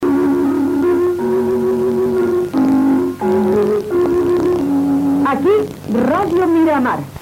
Sintonia i indicatiu de l'emissora
Sintonia basada en la melodia